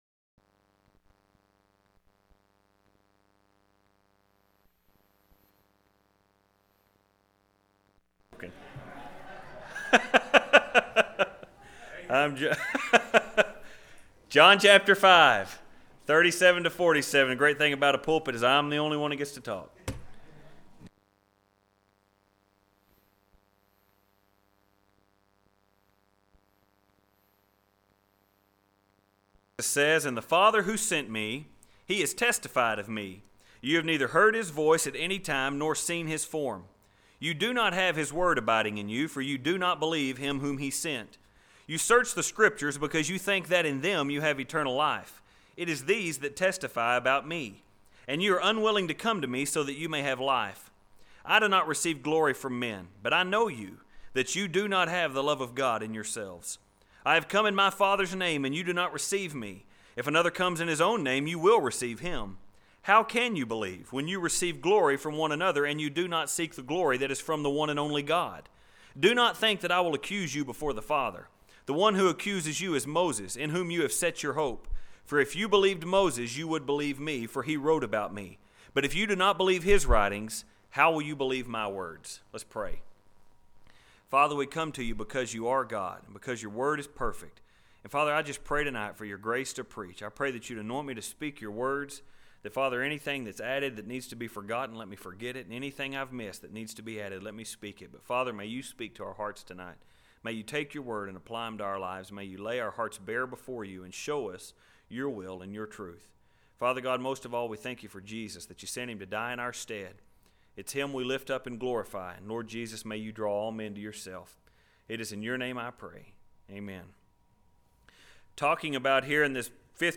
020-Jesus-The-Fathers-Sermon-John.mp3